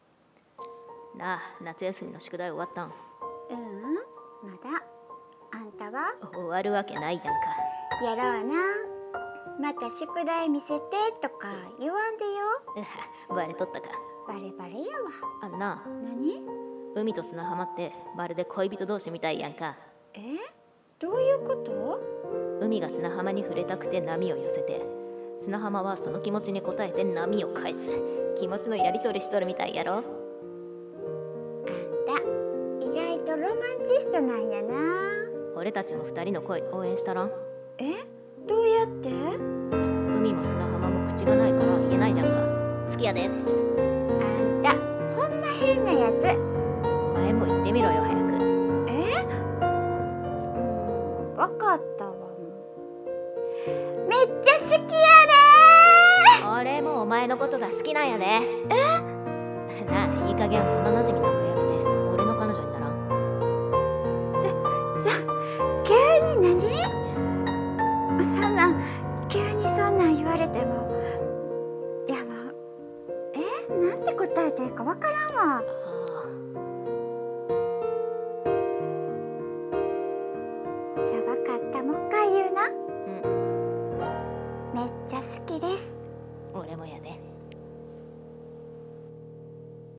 海と砂浜の恋】※恋愛コラボ声劇